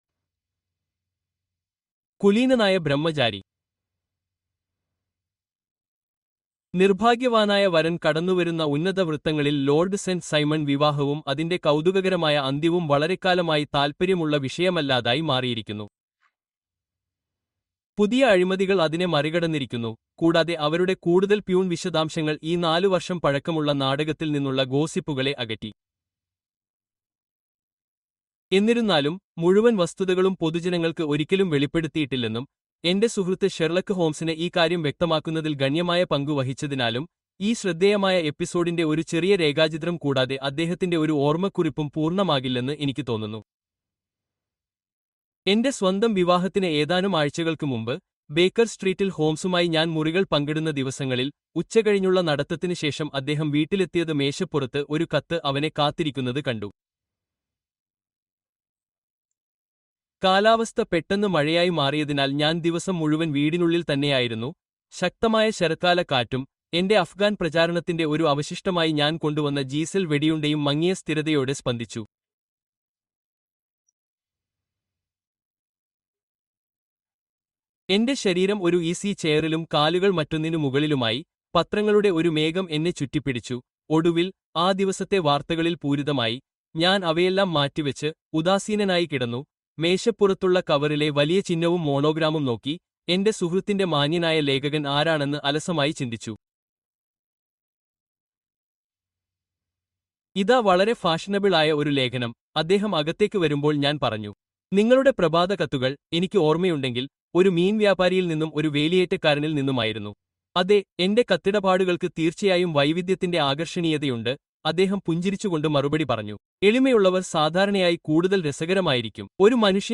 The Devil’s Foot: Sherlock Holmes’ Deadly Discovery (Audiobook)